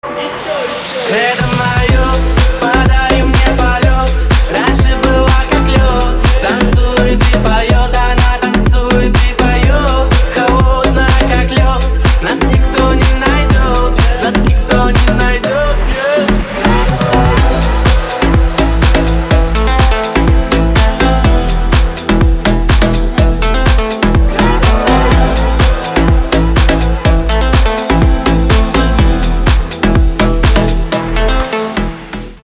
мужской вокал
громкие
dance
Electronic
Club House
электронная музыка